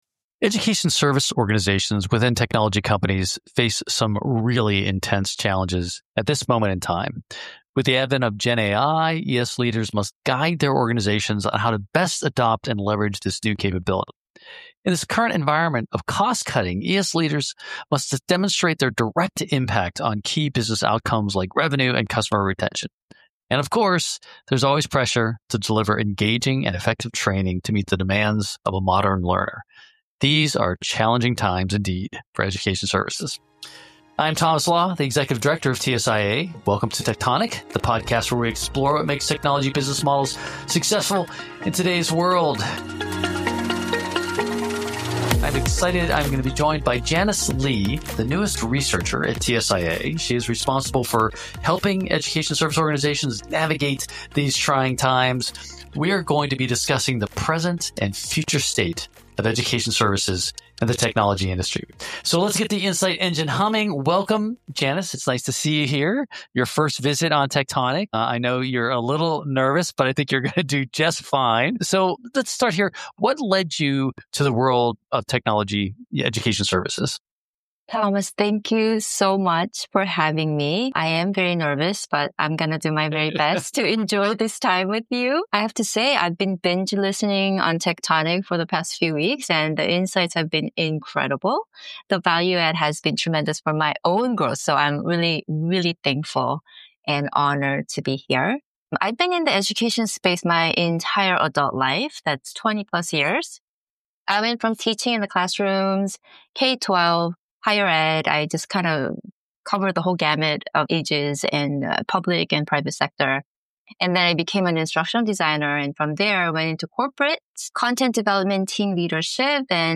This TSIA World INTERACT conference panel discussion offers valuable insights for technology and services professionals, drawing from expertise shared at TSIA World events.